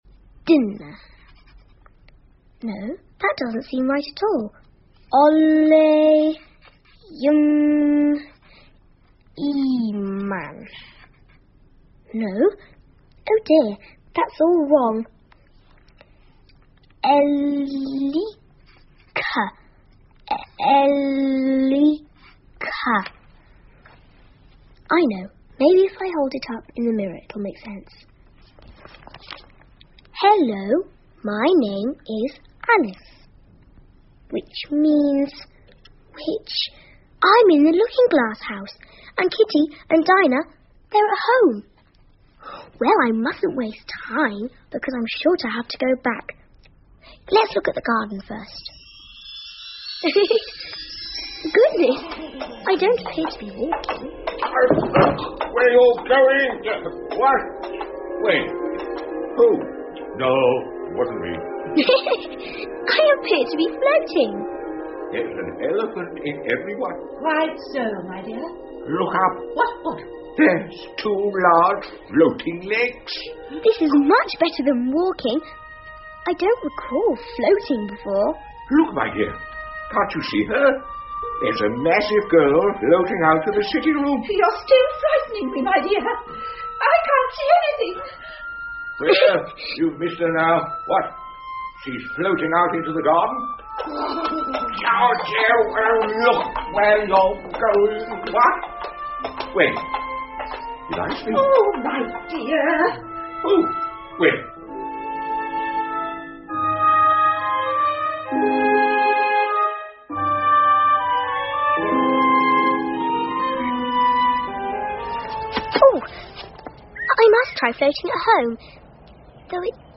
Through The Looking Glas 艾丽丝镜中奇遇记 儿童广播剧 3 听力文件下载—在线英语听力室